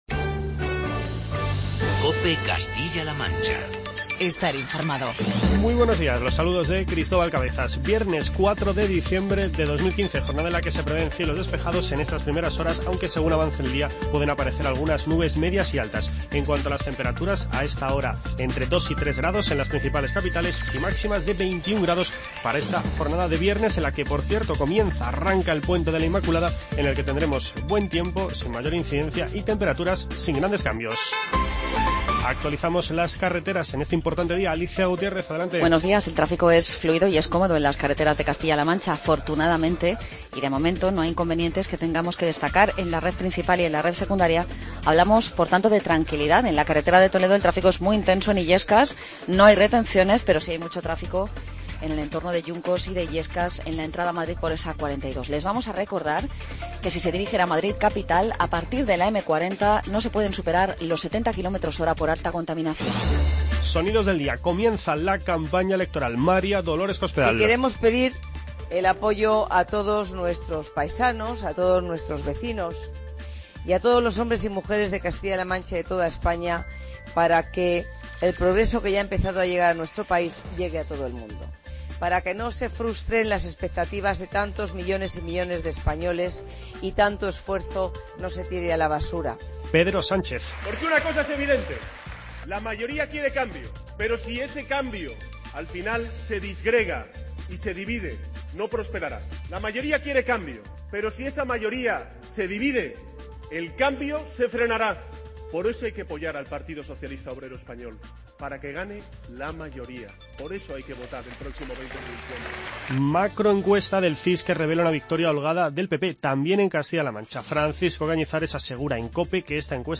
Informativo regional y provincial
Escucha las "Voces de los Protagonistas".